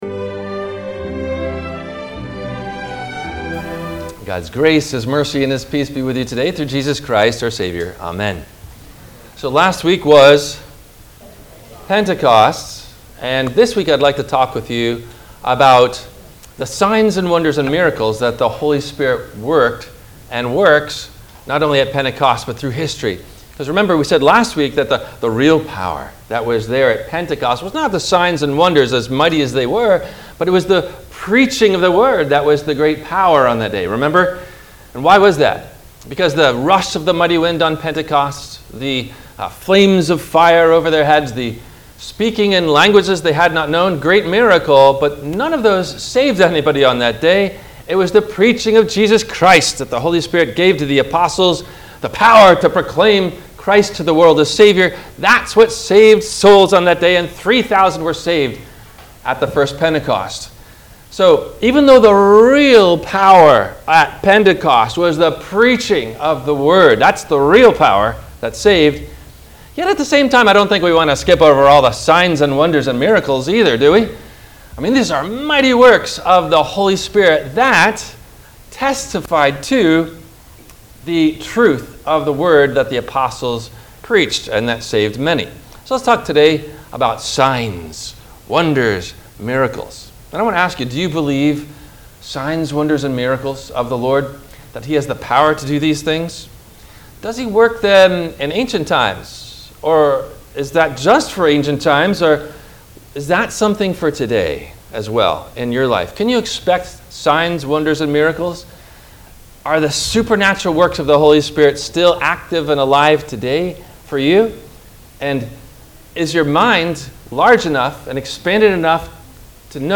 No questions asked before the Sermon message.